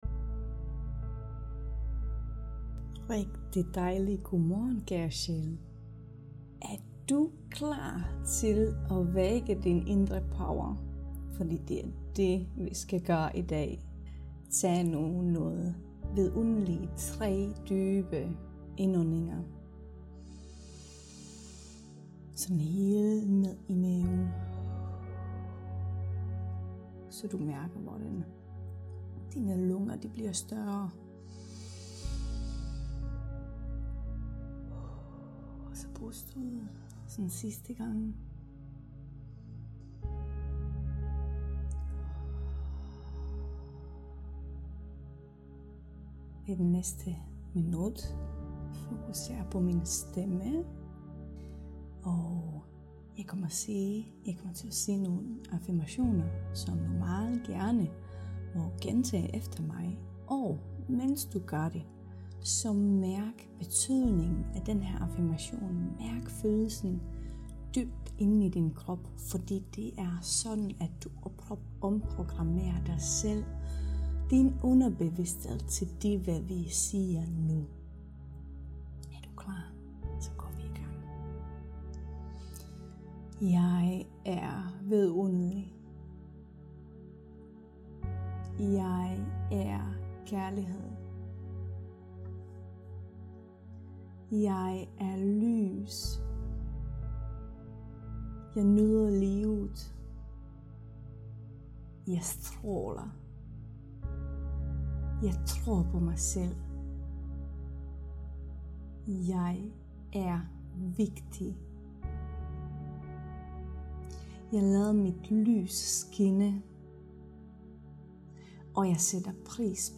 Lav gerne en energi tjek inden du hører til den første meditation.